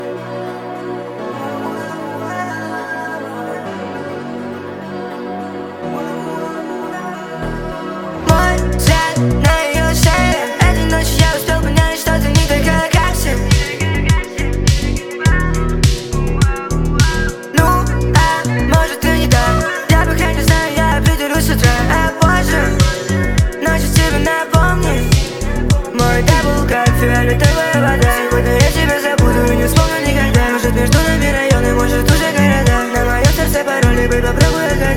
Жанр: Рэп и хип-хоп / Иностранный рэп и хип-хоп / Русские
# Hip-Hop